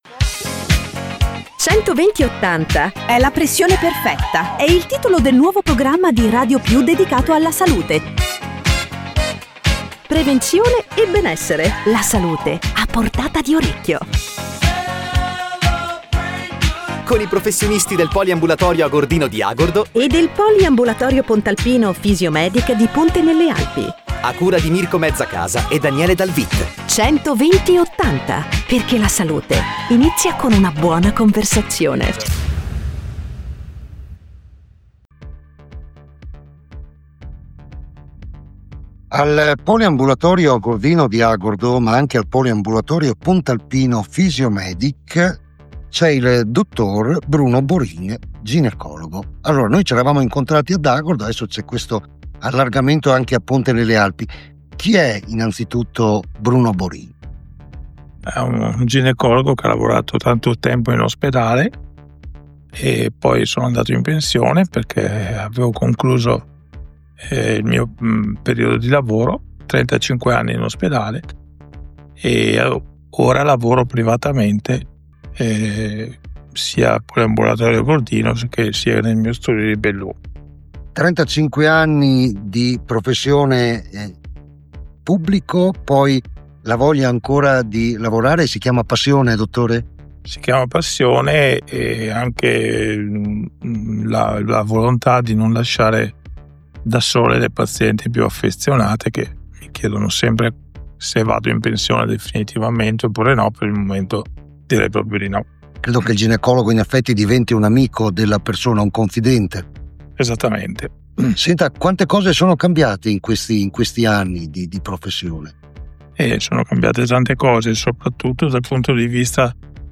I PROFESSIONISTI DEL POLIAMBULATORIO AGORDINO – LE INTERVISTE